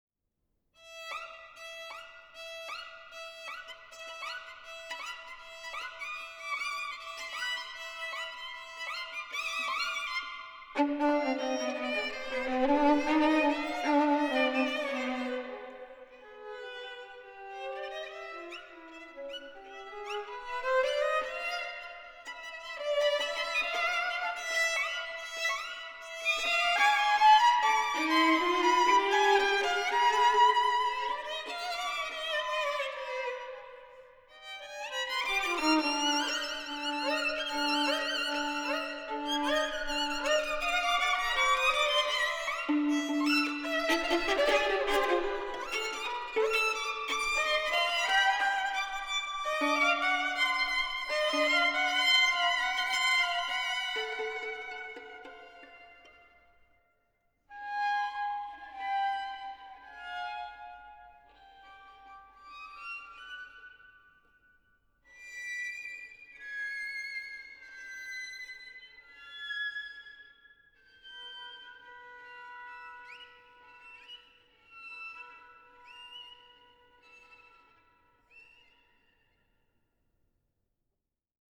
- I Calmo, quasi lontano
- II Tempestoso
- III Scherzando